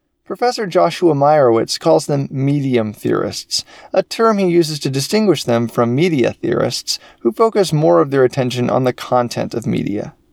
That roaring in a cardboard tube sound in the background is your air conditioner or your computer fan. That’s after hefty Noise Reduction.
I can get sorta close match with custom equalization (pitch change), volume compression (denser) and noise reduction (background sound).